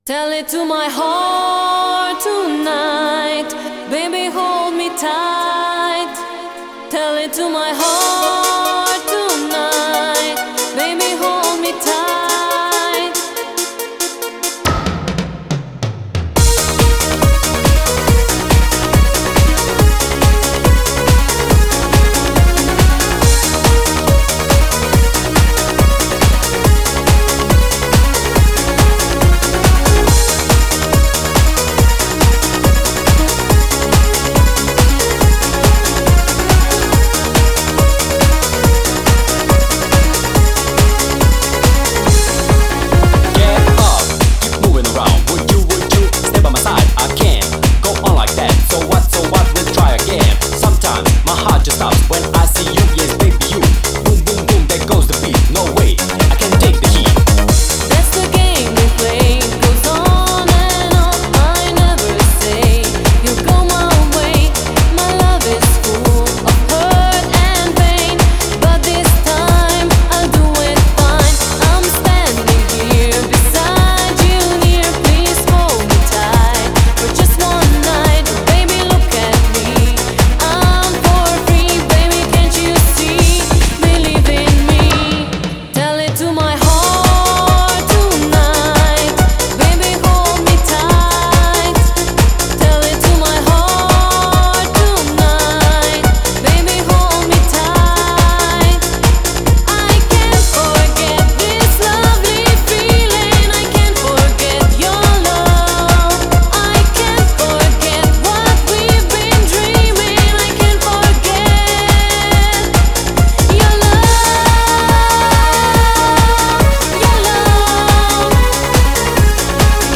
Genre: Eurodance.